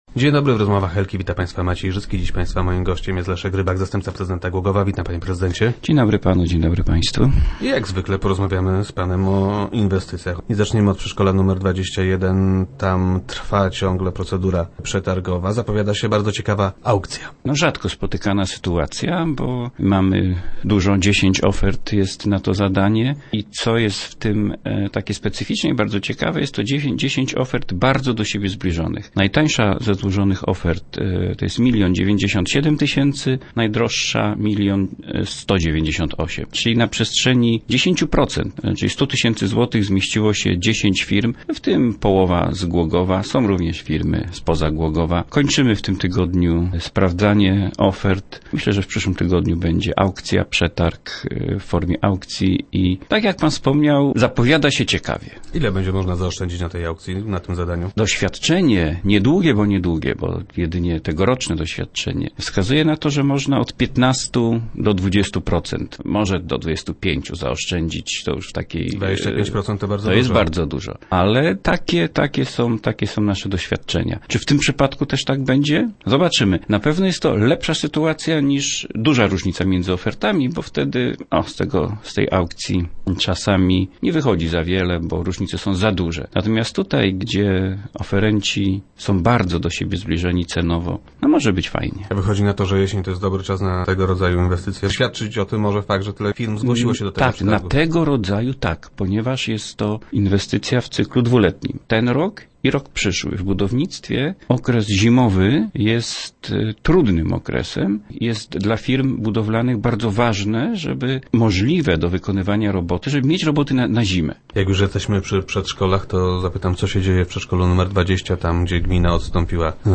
Firma, która wygrała, spełnia nasze warunki i już w najbliższych dniach zostanie podpisana z nią umowa - mówił zastępca prezydenta w Rozmowach Elki.